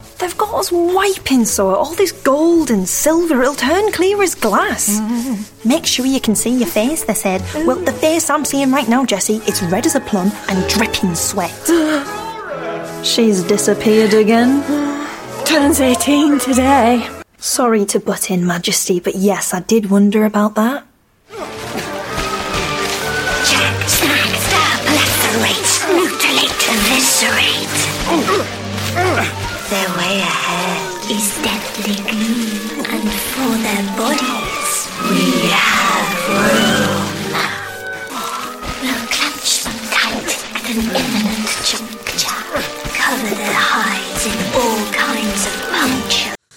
Teens-30s. Female. North East.